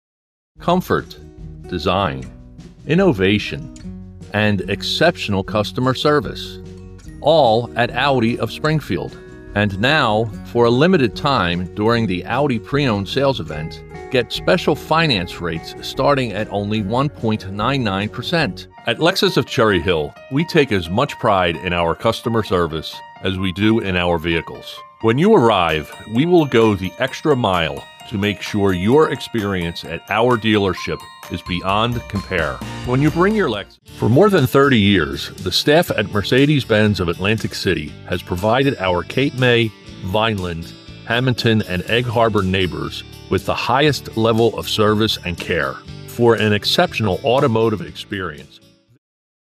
Never AI, always authentic.
Professional-grade equipment and acoustic treatment deliver broadcast-ready audio that rivals major market studios in New York and Los Angeles.
Luxury Auto Demo